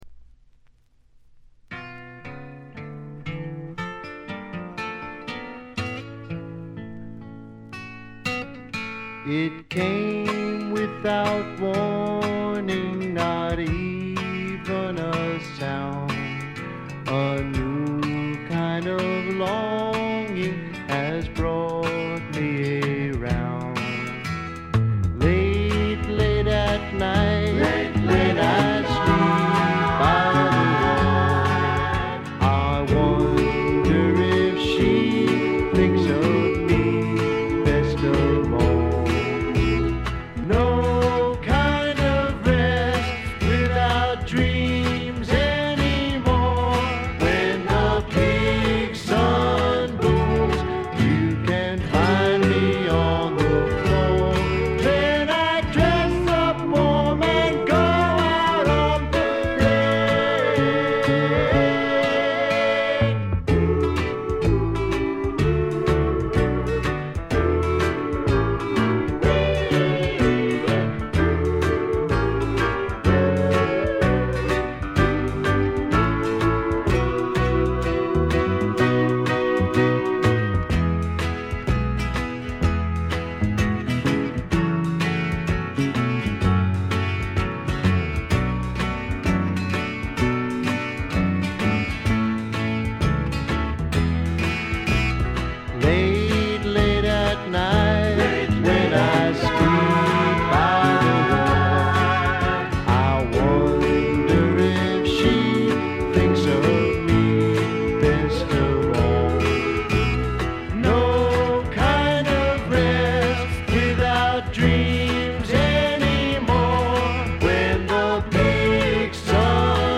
軽微なチリプチ程度。
試聴曲は現品からの取り込み音源です。
Recorded At - Sound Exchange Studios